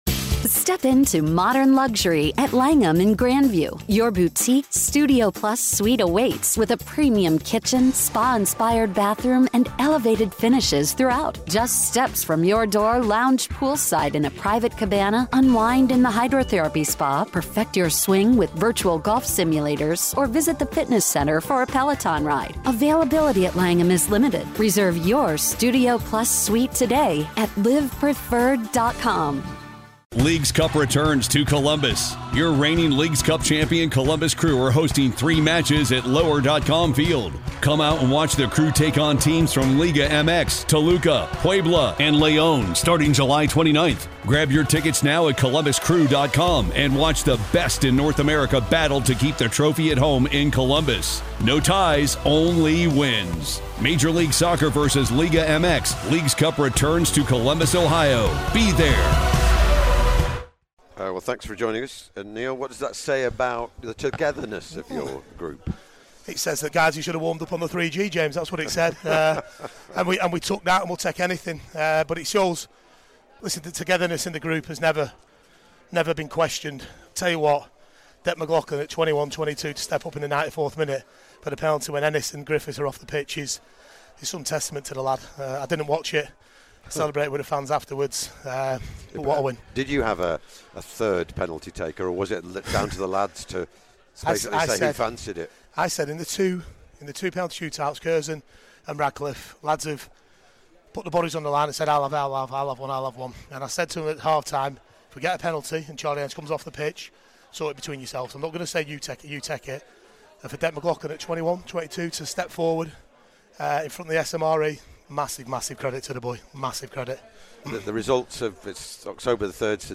Post Match Interview